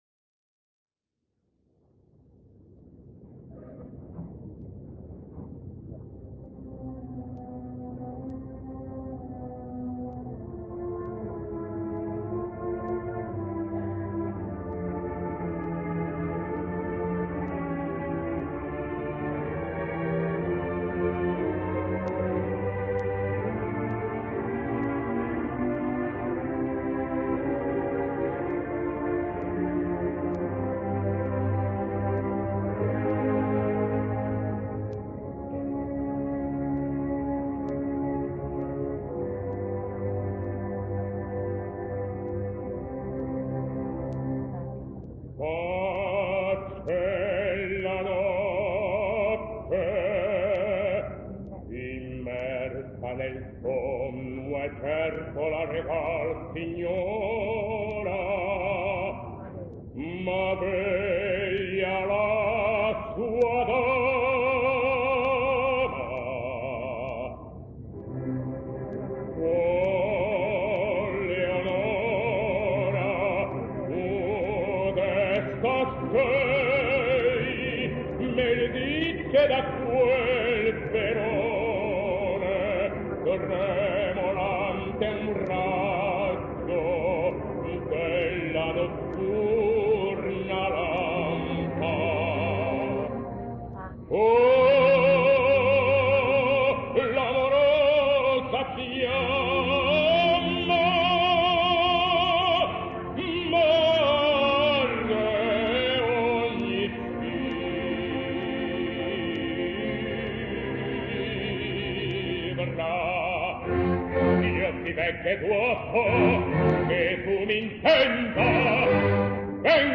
He developed into one of Rio's (and Brazil's) foremost tenors in both opera and concert.